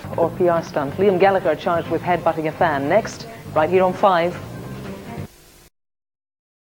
The original 5 news, presented from the newsroom at ITN.
Kirsty gives a quick look at whats after the break.